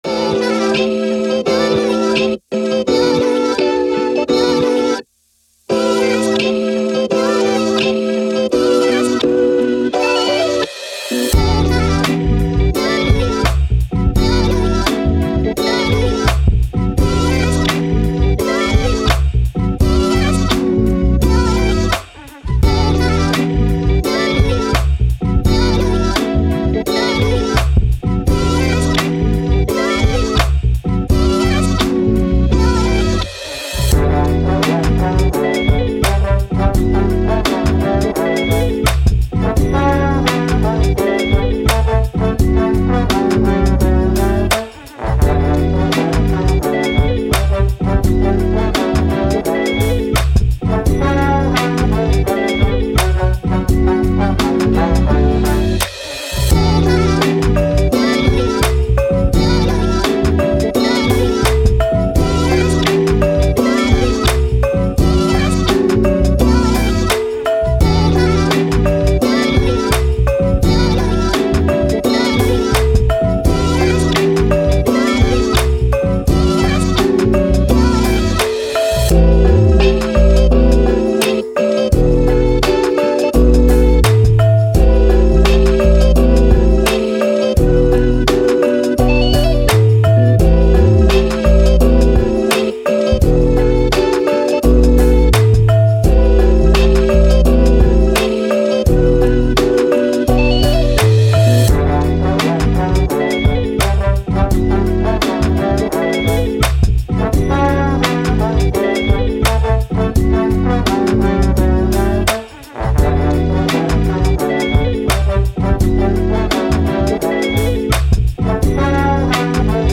Hip Hop, Boom Bap, Positive, Action, Story